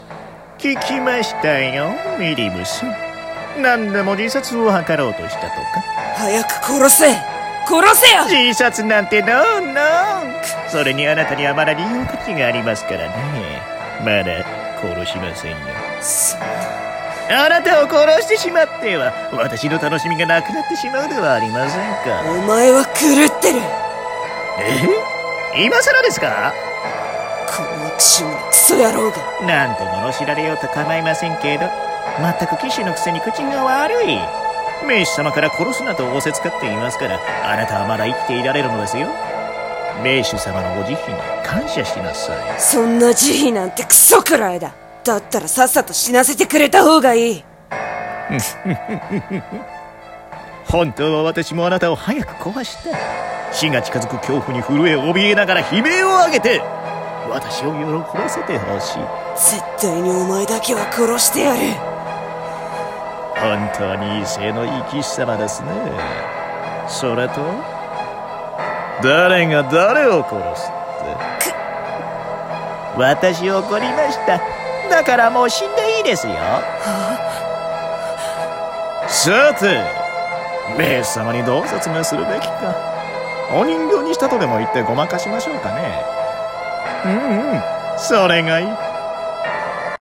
【幽閉の騎士】声劇台本